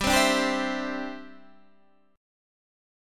Listen to G6sus strummed